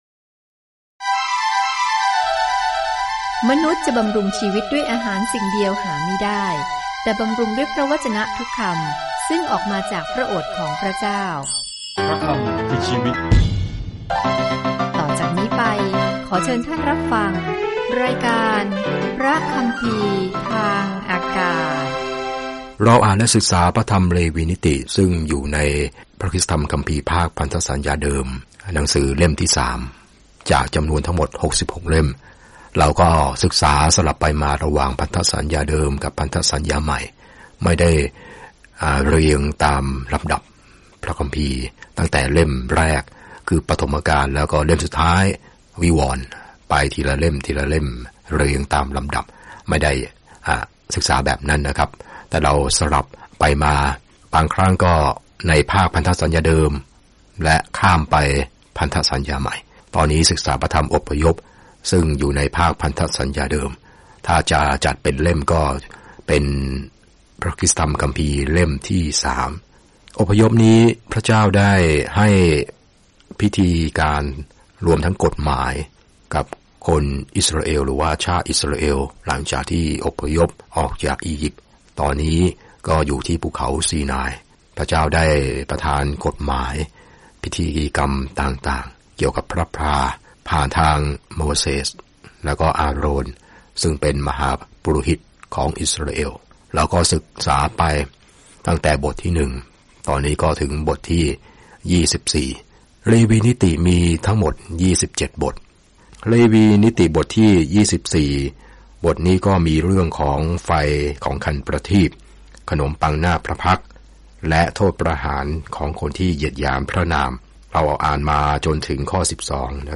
เดินทางทุกวันผ่านเลวีนิติในขณะที่คุณฟังการศึกษาด้วยเสียงและอ่านข้อที่เลือกจากพระวจนะของพระเจ้า